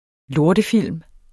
Udtale [ ˈloɐ̯də- ]